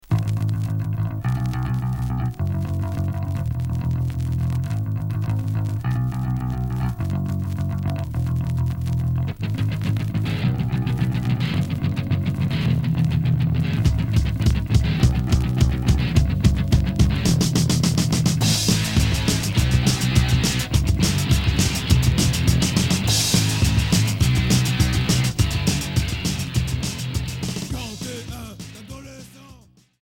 Oi